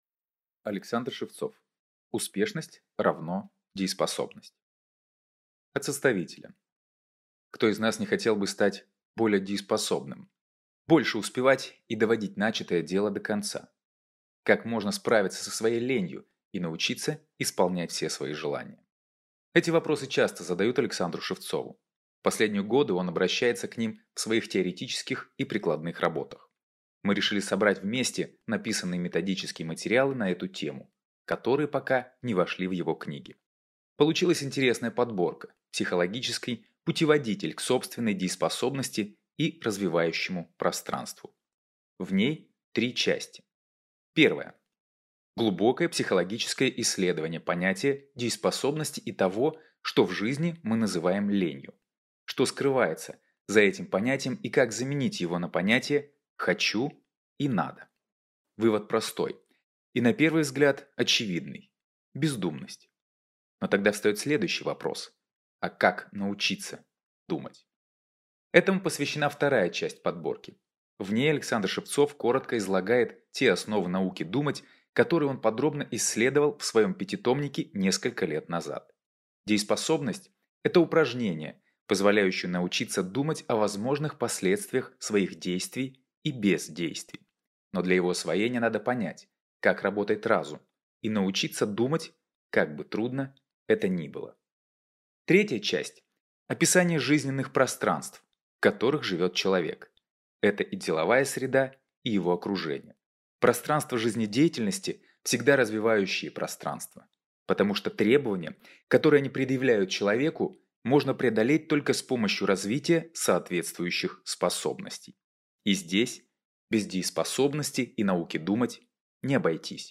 Аудиокнига Успешность = дееспособность | Библиотека аудиокниг